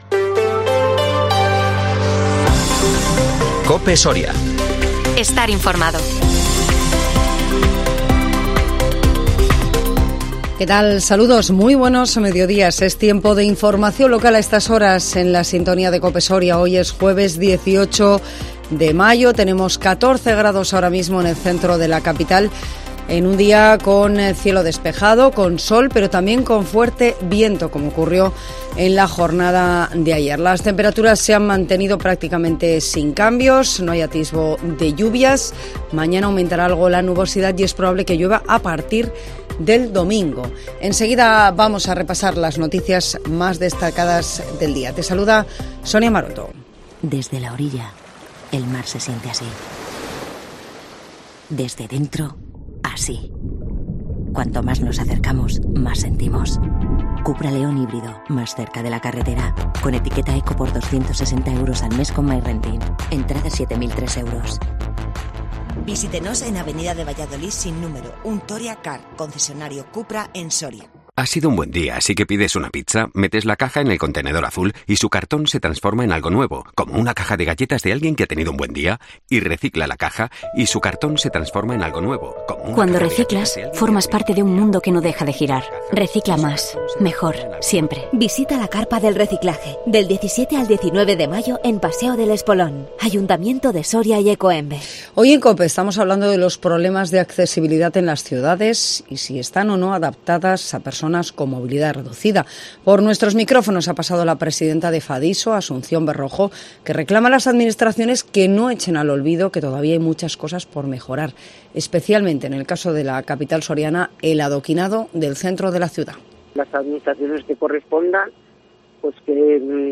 INFORMATIVO MEDIODÍA COPE SORIA 18 MAYO 2023